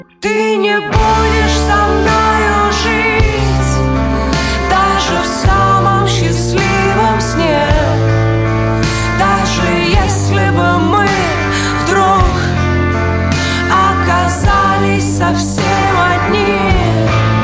• Качество: 128, Stereo
рок